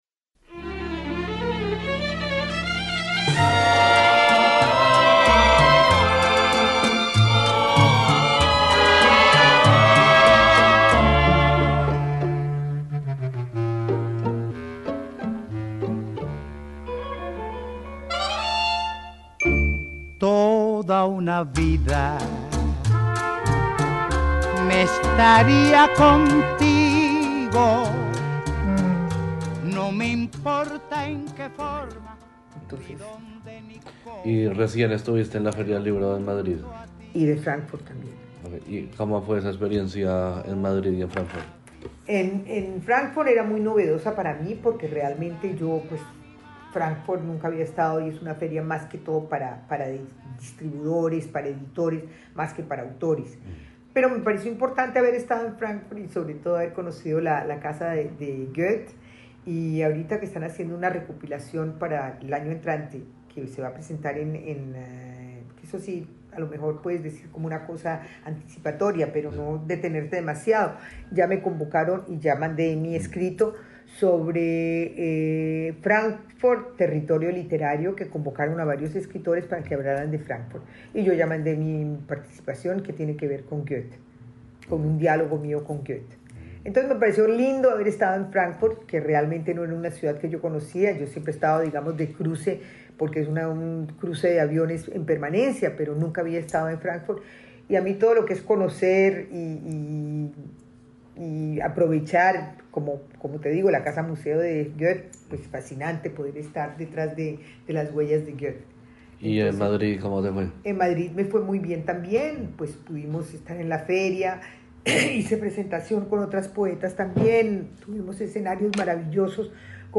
Esta es la segunda y última parte de la entrevista